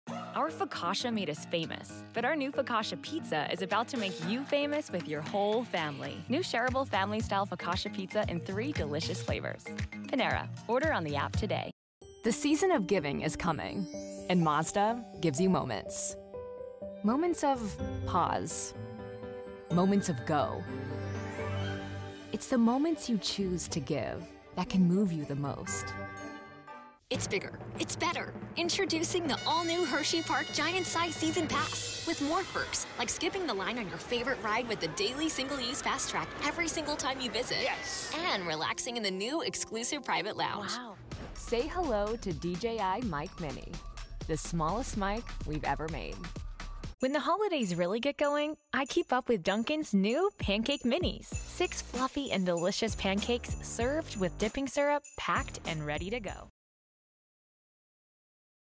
English (American)
Sultry
Conversational
Confident